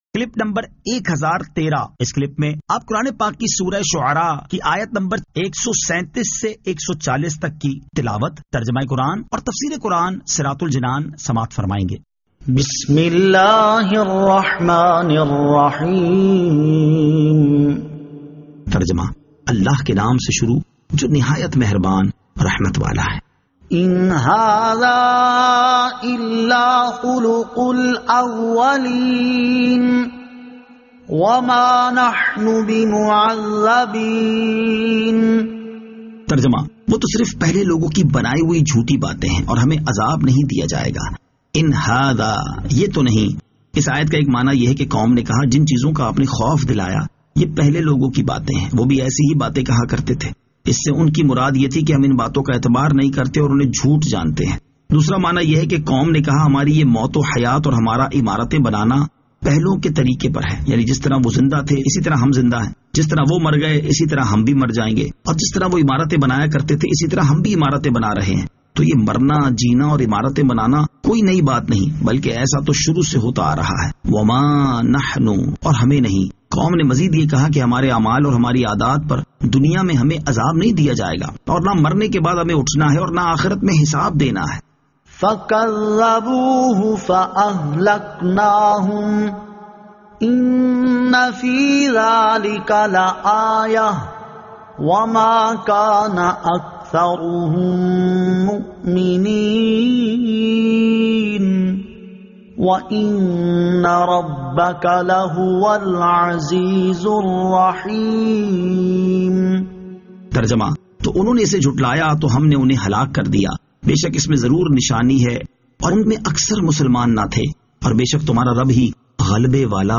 Surah Ash-Shu'ara 137 To 140 Tilawat , Tarjama , Tafseer